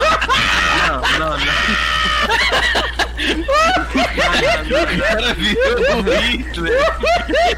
Risada